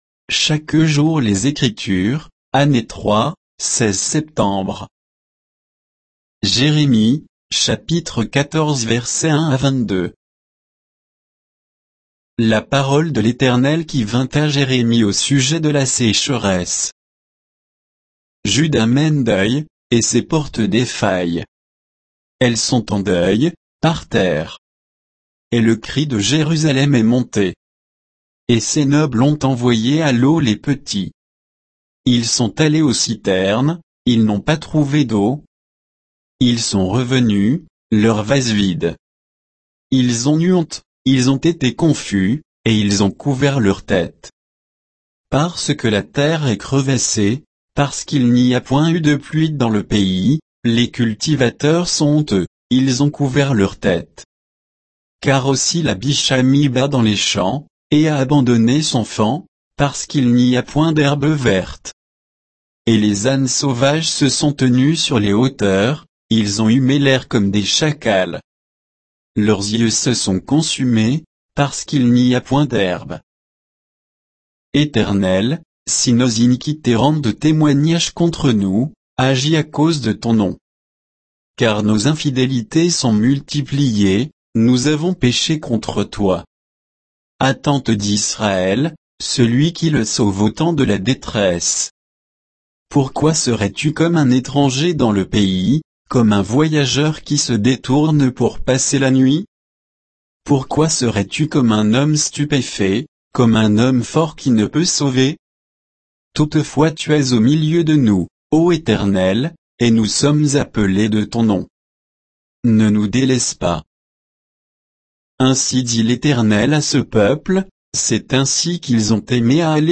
Méditation quoditienne de Chaque jour les Écritures sur Jérémie 14